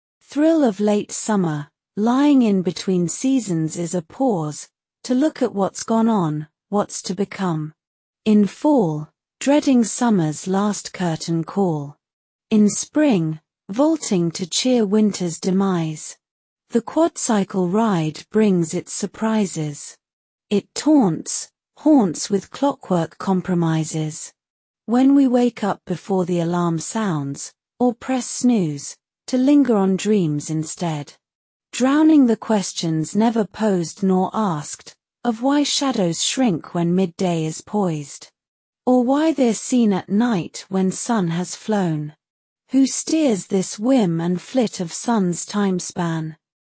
I have to say that I am shocked by the sound of your voice, I never expected you to sound British. Or female.